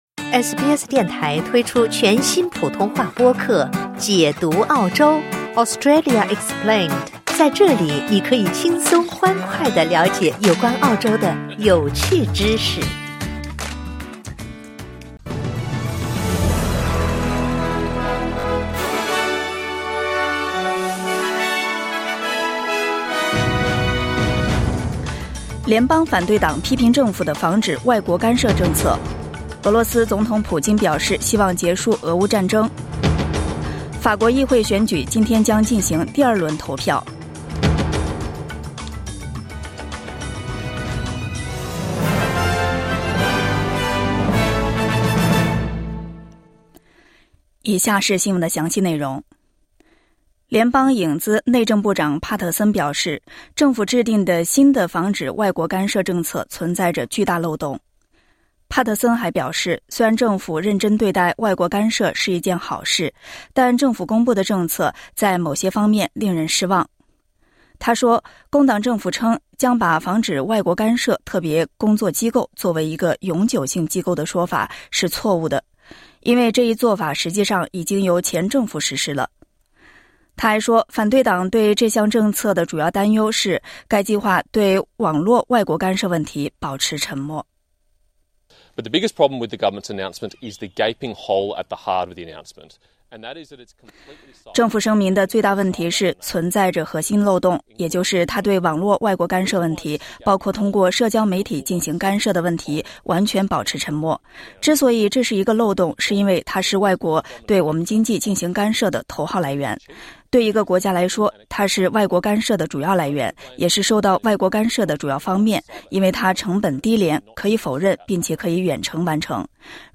SBS早新闻（2024年7月7日）